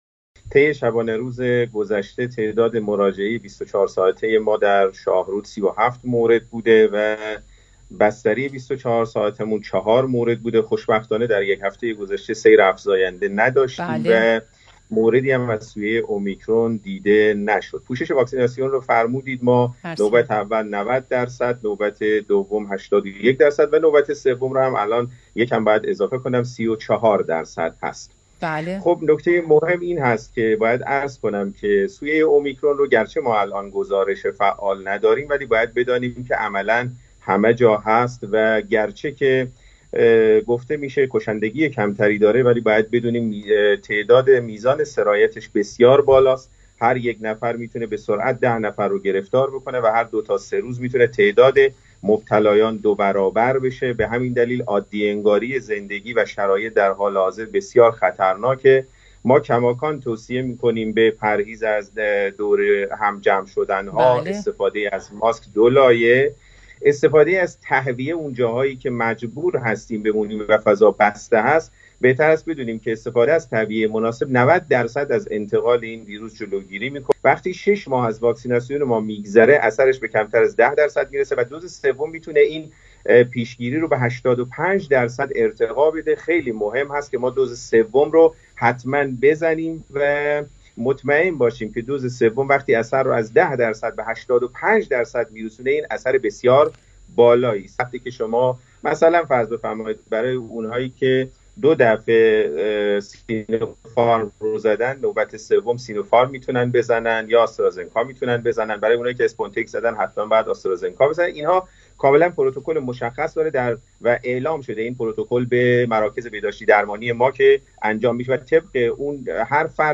در مصاحبه با خبر۲۰ سیمای استان- شامگاه شنبه ۴ دی ماه سال جاری